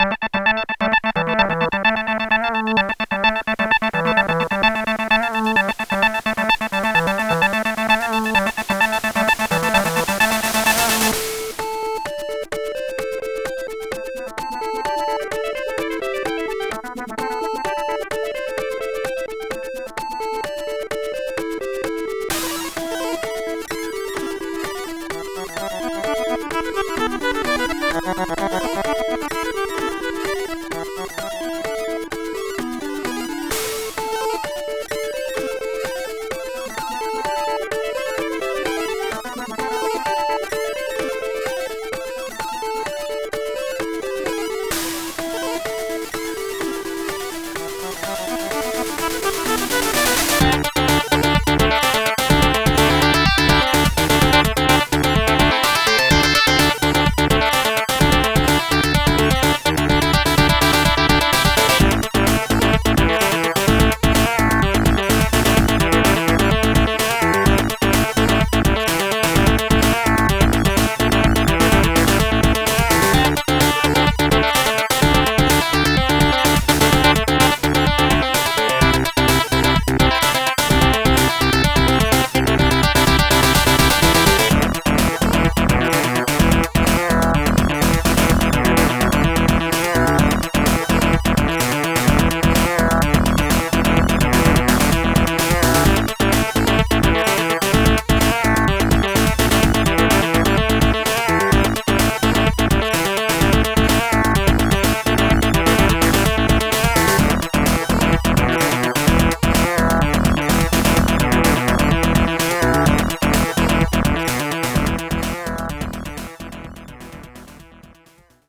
Level 1 music OPL2 vs.